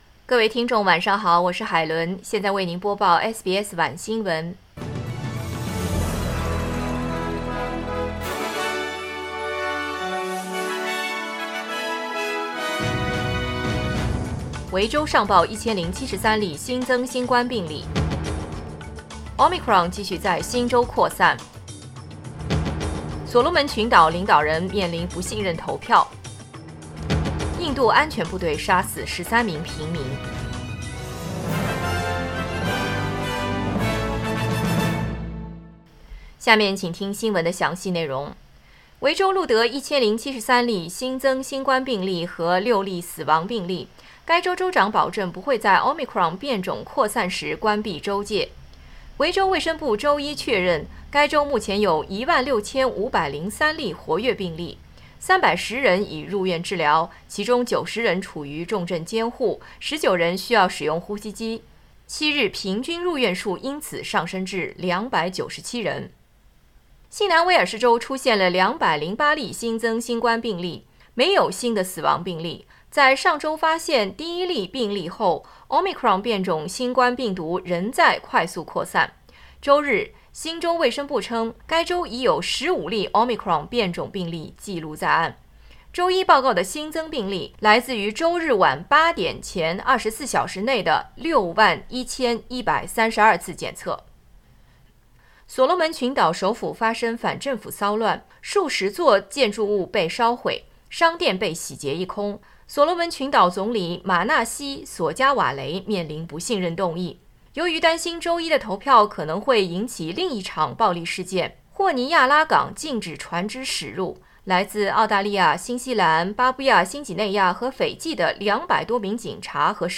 SBS晚新闻（2021年12月6日）
SBS Mandarin evening news Source: Getty Images